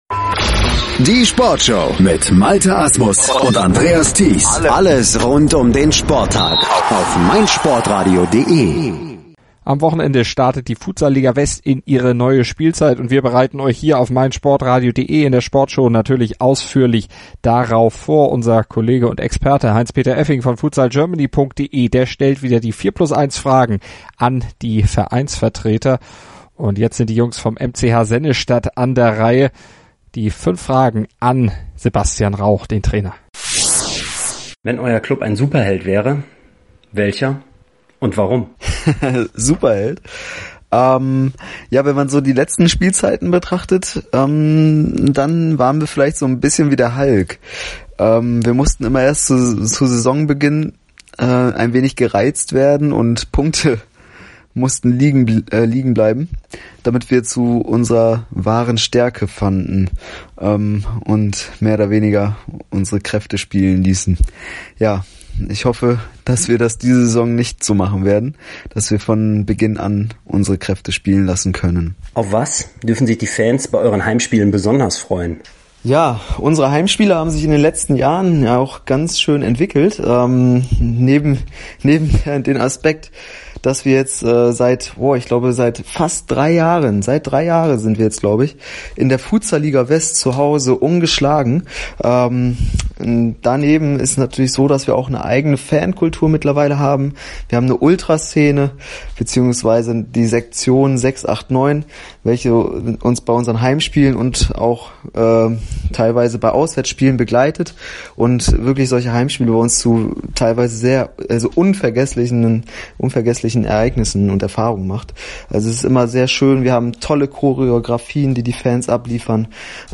unseres Interviewformats 4+1 fünf Fragen zur neuen Saison gestellt.